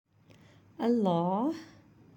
HOW to Say ALLAH Tutorial